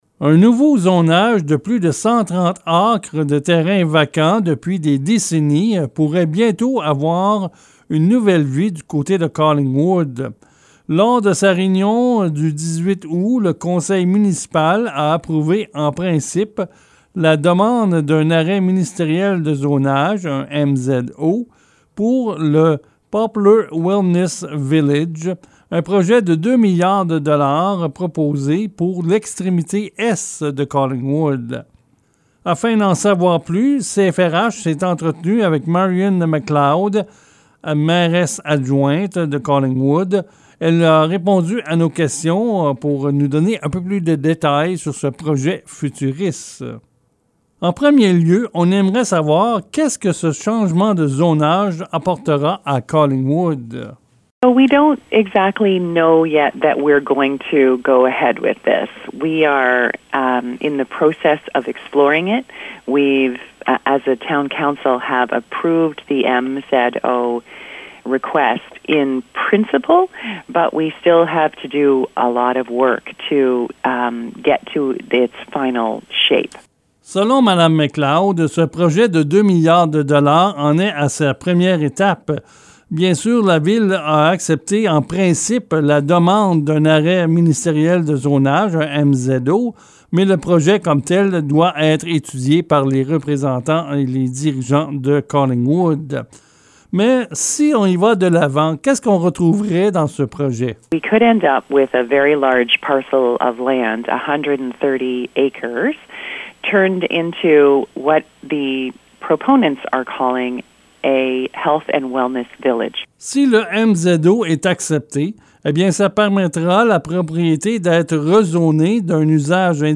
La mairesse adjointe Mariane McLeod nous a offert quelques précisions au sujet de ce projet futuriste.